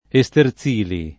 [ e S ter Z& li ]